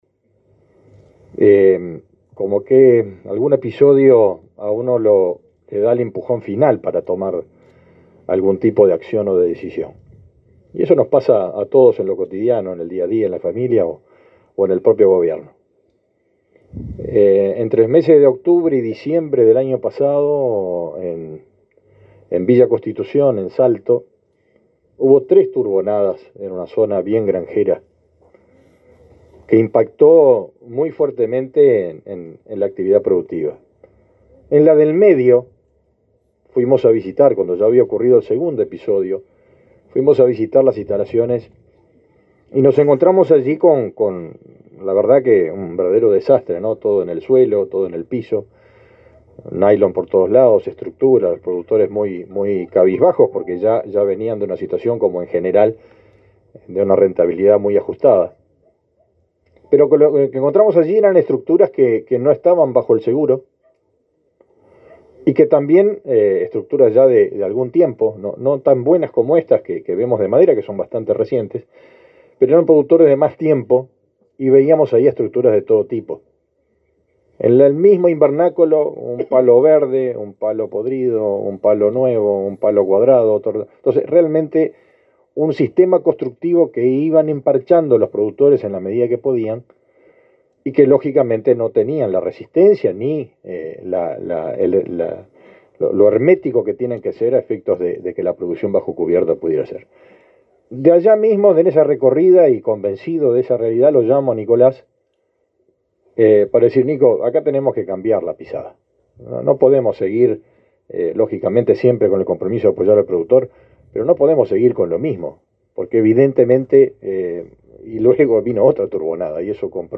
Palabras del ministro de Ganadería, Fernando Mattos
El ministro de Ganadería, Fernando Mattos, participó en el lanzamiento del Plan de Apoyo a la Modernización de la Producción Hortícola bajo Protección
La actividad se realizó este lunes 29 en la localidad de Canelón Chico, departamento de Canelones.